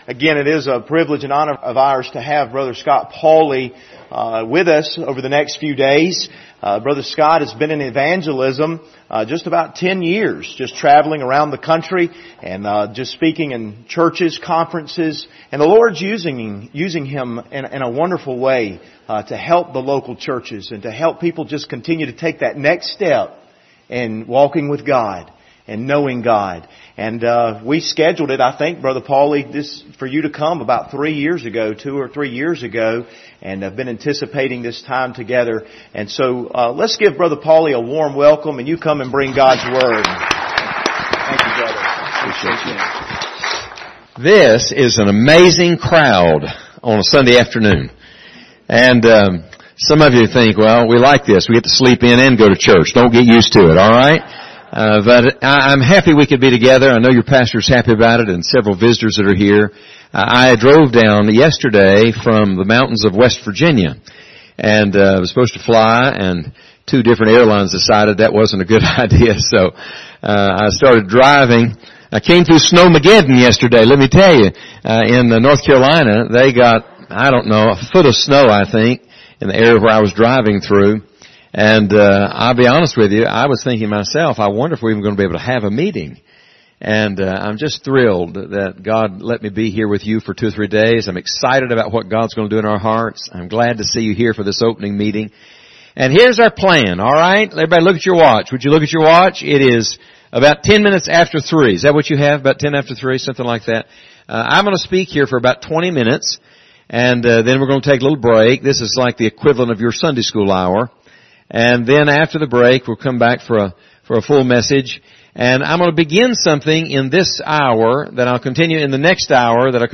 Passage: Acts 4:32-37 Service Type: Revival Service Topics